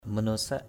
/mə-no-saʔ/ manosak m_n%xK [Cam M] (d.) loài người, nhân loại = genre humain = mankind, human.